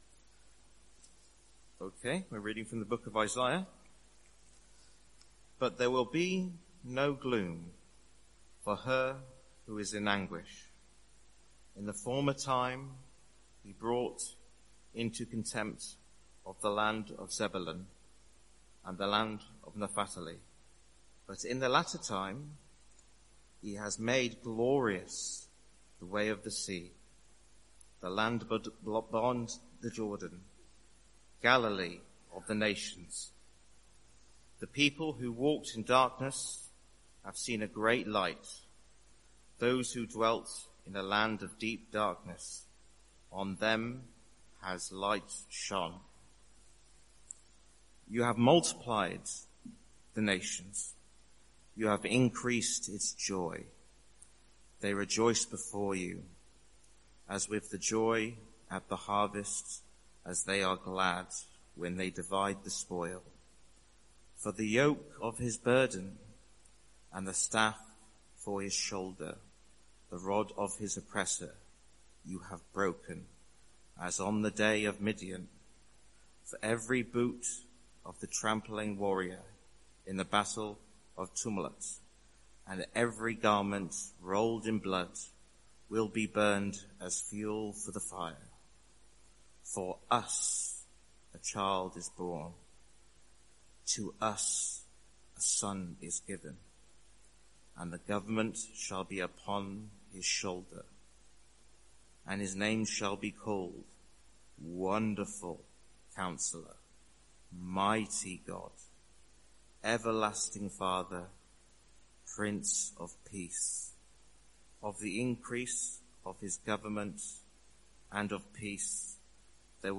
Sermon Series: The Prophecy of Isaiah | Sermon Title: For to us a Child is born